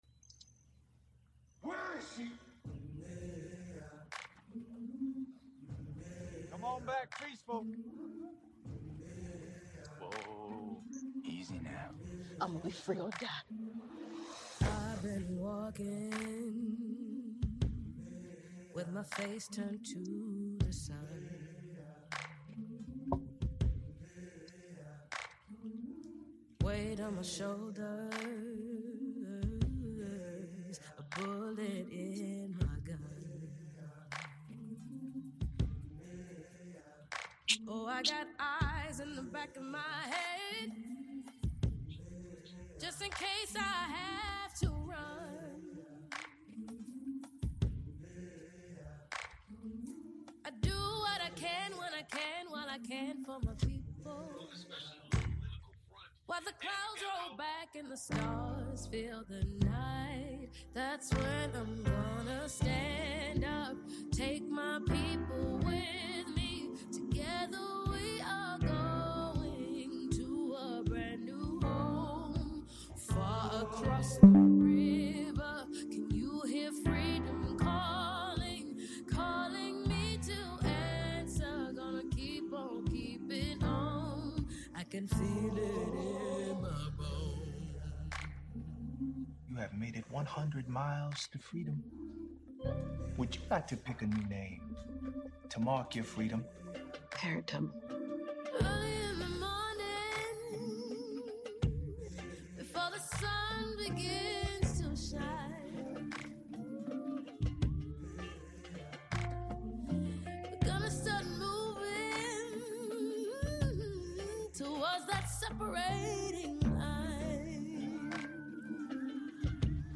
for a compelling conversation in honor of Black History Month.